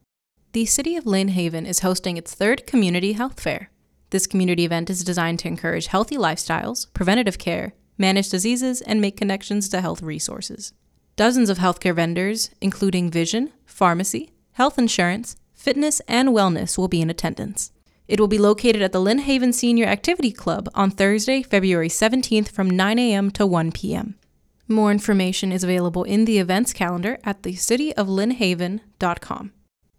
Lynn Haven 3rd Health Fair PSA
LynnHaven_3rd_Health_Fair_PSA.wav